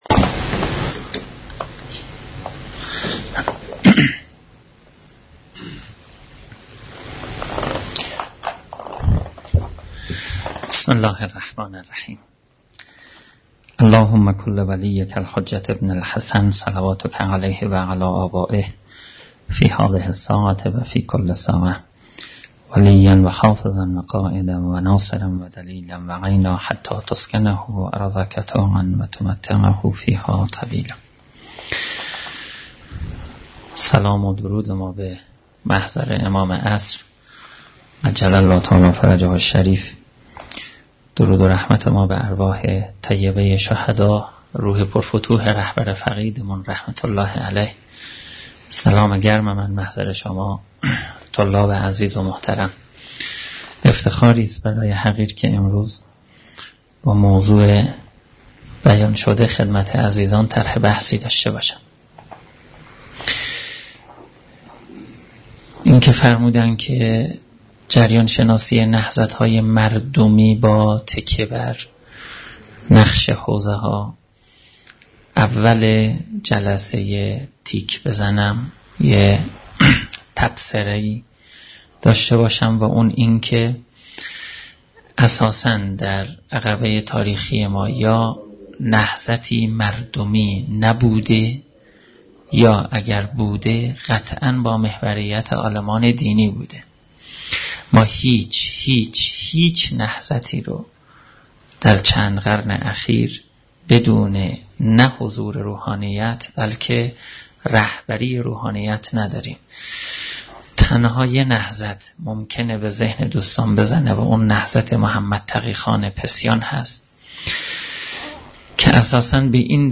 دومین نشست همایش تبیین نسبت حوزه و انقلاب